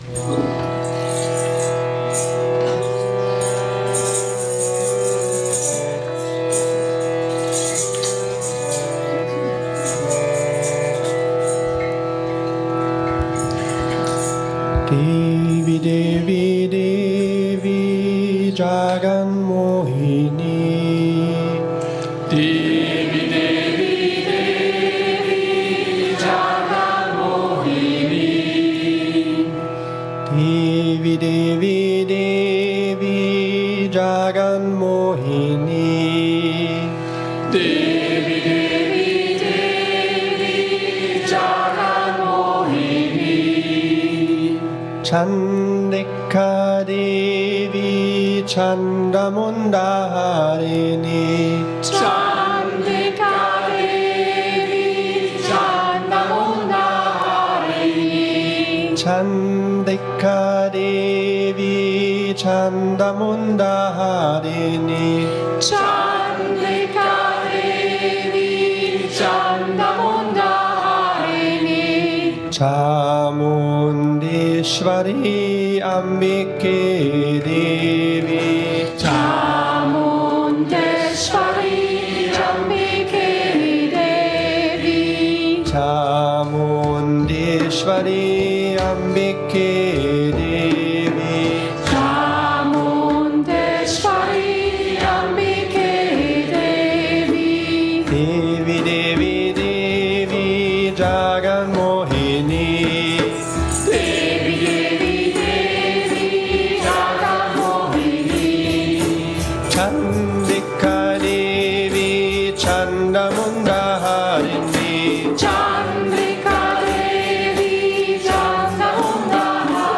Sometimes enthusiastic, sometimes in a more meditative mood; sometimes classical, sometimes more modern.
Mantras, Kirtan, Recitations of Shlokas, Stotras, Suktams.
“Devi Devi Devi” is a kirtan with great dynamism, great joy, and great lightness. You can start this kirtan slowly and gradually speed it up.